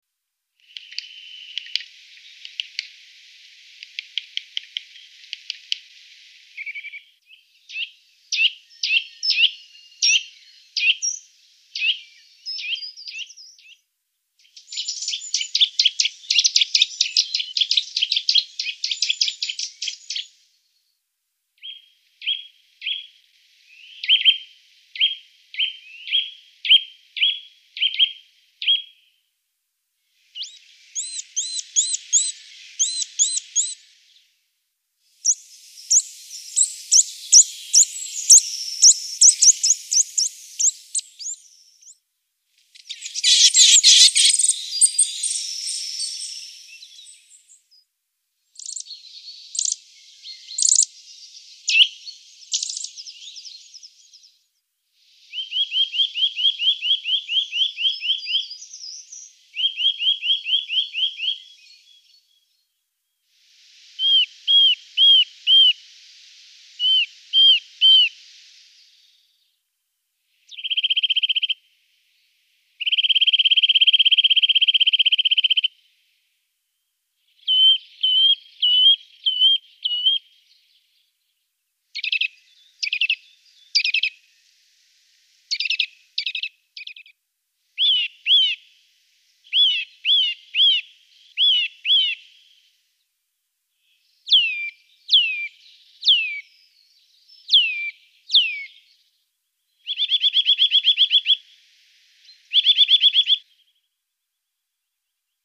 Sittelle torchepot
Sittelle torchepot.mp3